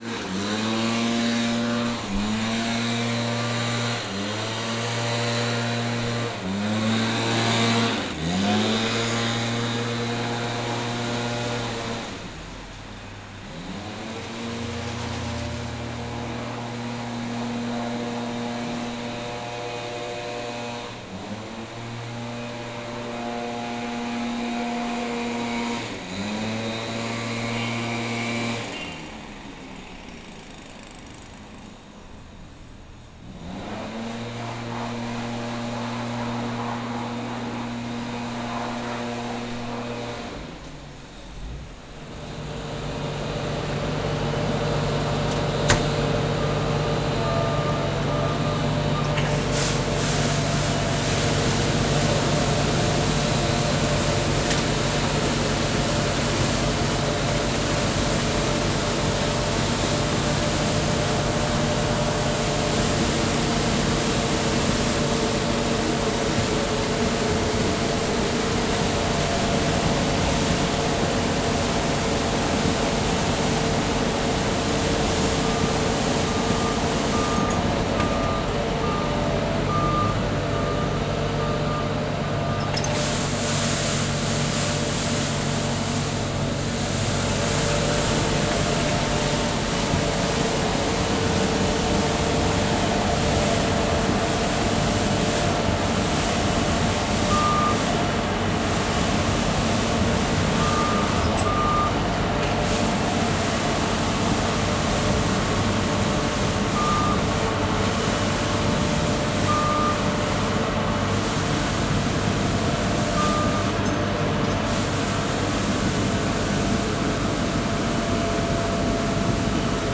Todas las platanáceas se ponen de acuerdo en otoño para dejar caer sus hojas y si es en domingo, no es excusa para que el departamento de limpieza de la ciudad saque sus máquinas para no dejar rastro de las hojas. En mi ciudad este departamento es muy ruidoso.
[ENG] All agree Platanaceae drop their leaves in autumn and when that happens on Sunday there is no excuse for the housekeeping department of the city to take out their machines and try to leave no trace of the leaves. In my town this department is very noisy.
neteja-de-la-tardor.wav